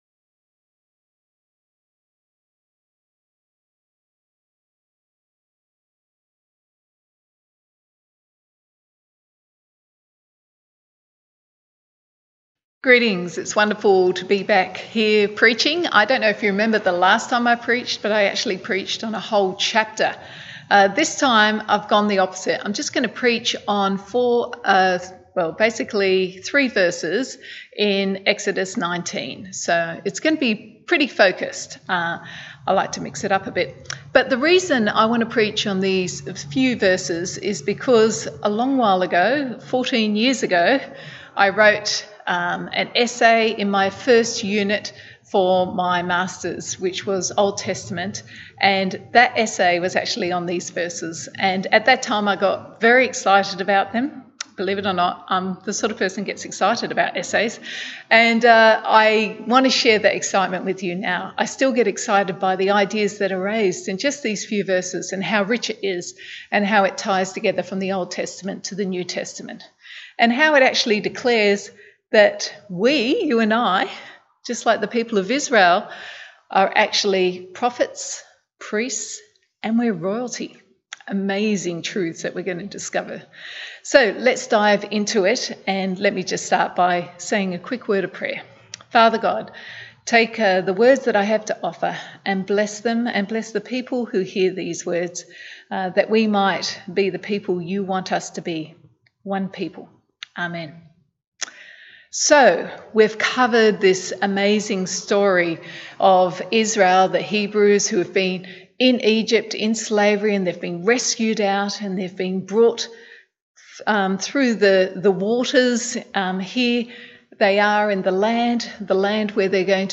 Bible Text: Exodus 19:4-8 | Preacher